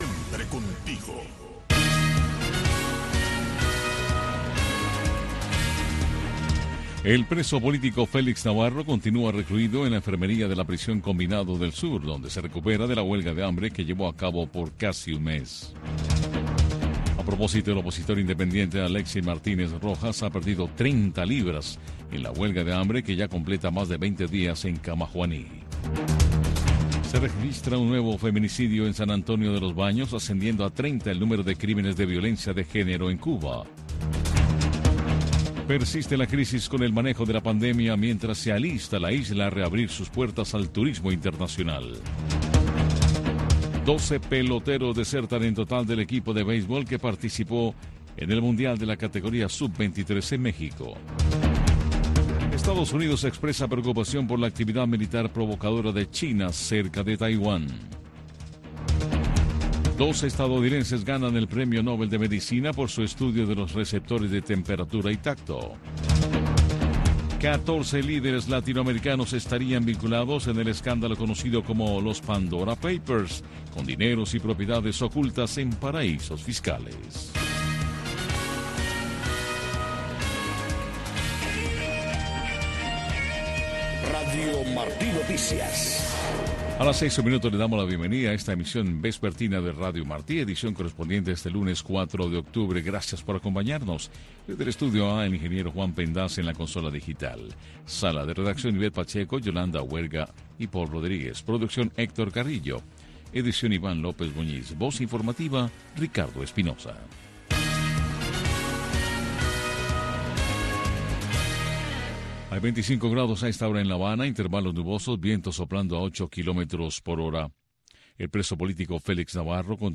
Noticiero de Radio Martí 6:00 PM